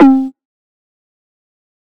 Bongo [5].wav